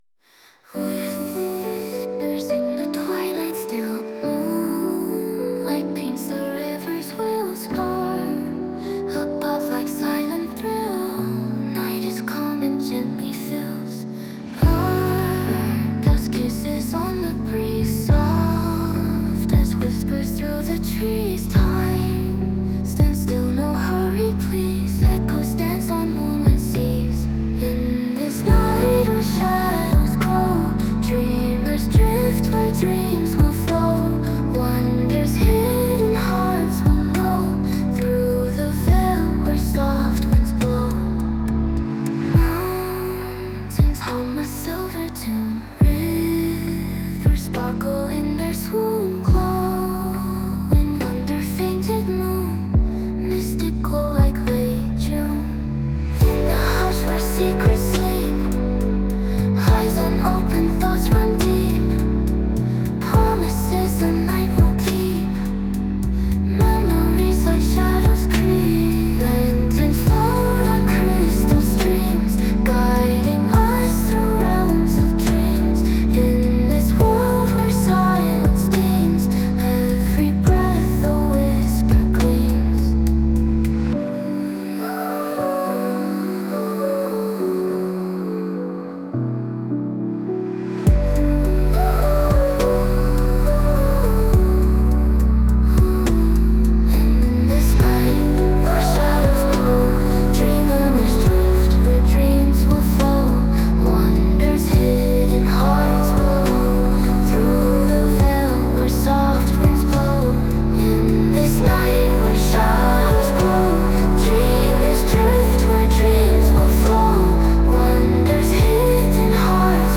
분위기 몽환적인
BPM 60
몽환 꿈결같은 bgm